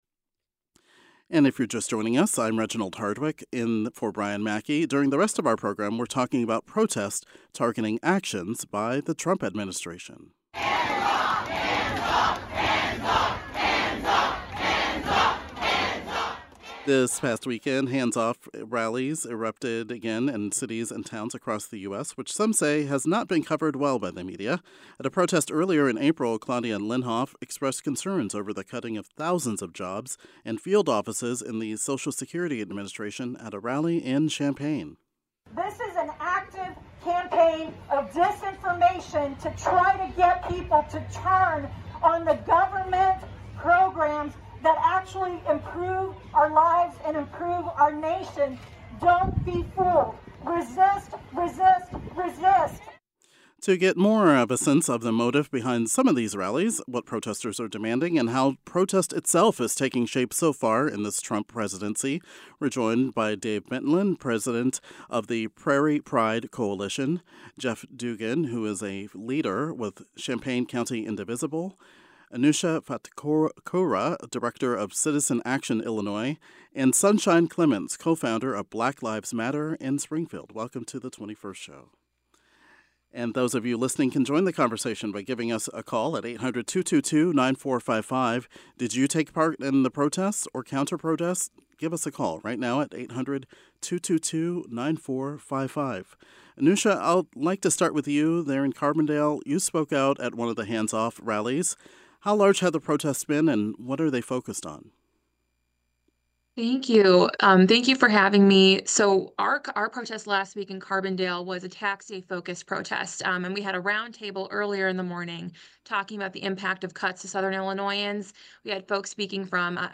Leaders from various political advocacy organizations around the state discuss what protestors are demanding and how protest itself is taking shape so far in this Trump presidency.